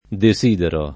deS&dero] — nell’uso ant., anche disiderare: disidero [